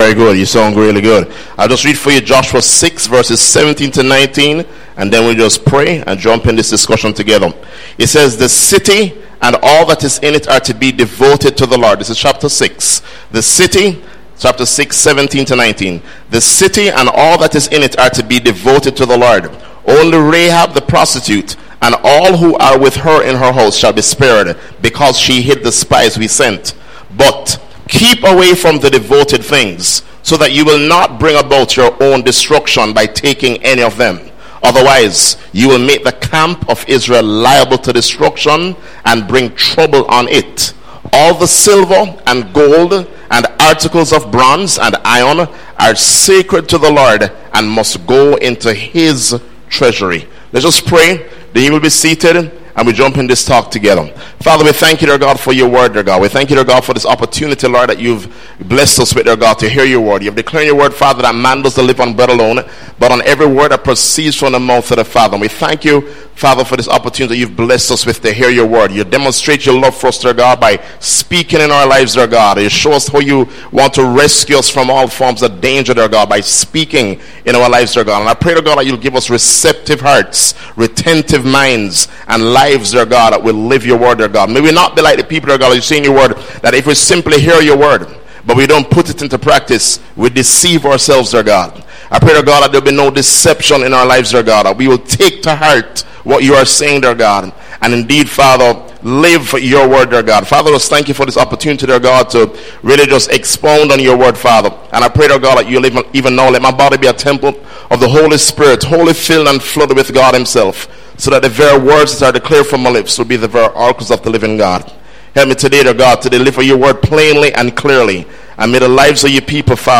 New Year’s Eve Message 2017 – One bad decision…far-reaching consequences